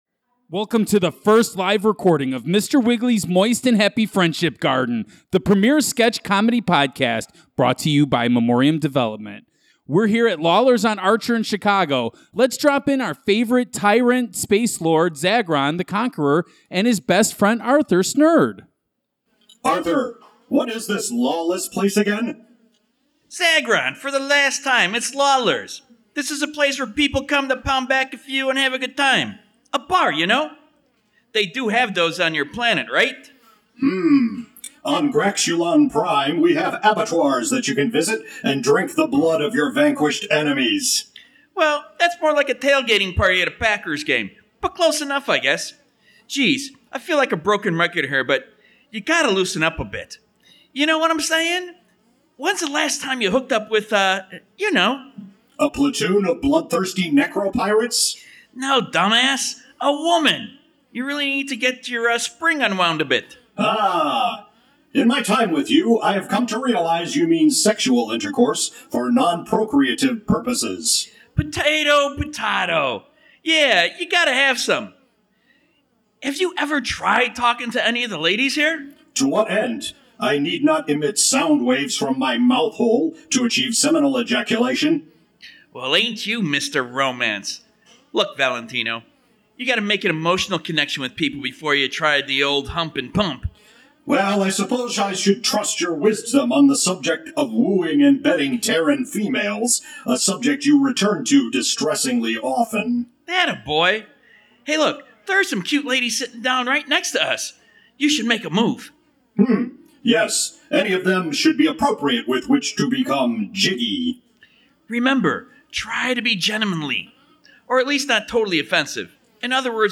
SPECIAL EPISODE: Live at Lawlors in Chicago: Presenting Mr. Wigglys first LIVE show, recorded in November 2019 at Lawlors pub in Chicago. Enjoy the hijinks, laffs, and flop sweat as we give you all-new comedy and run through some old favorites.